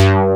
MOOGBAS3.wav